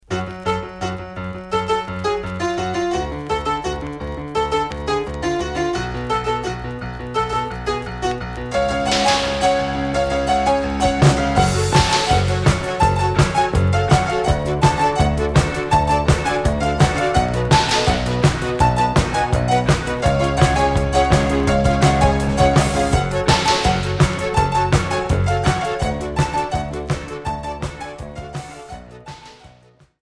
New wave 2ème 45t retour à l'accueil